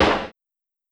Snare (A Night Off).wav